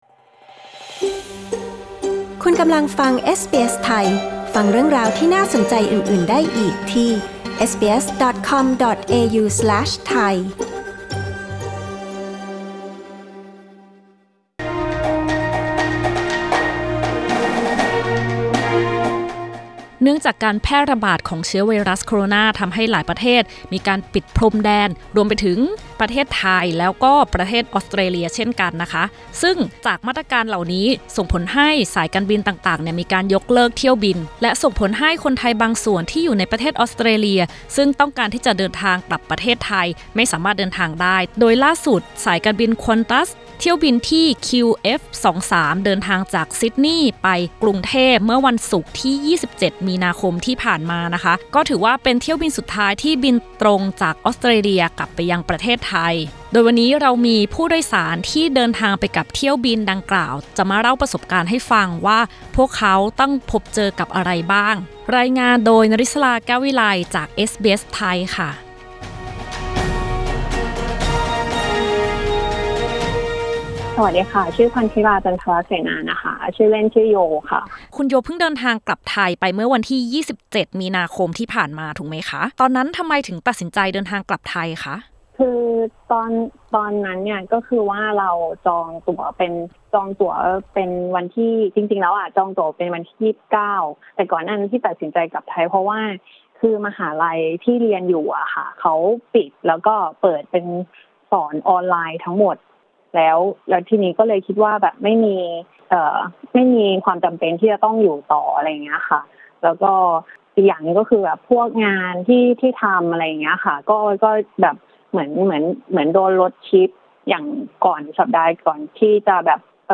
ฟังบทสัมภาษณ์ของผู้โดยสารจากเที่ยวบินตรงสุดท้ายที่ออกจากท่าอากาศยานนานาชาติซิดนีย์ คิงส์ฟอร์ด สมิธ ไปยังท่าอากาศยานนานาชาติสุวรรณภูมิ เมื่อวันที่ 27 มีนาคม ว่าพวกเขาต้องเจอกับอะไรบ้าง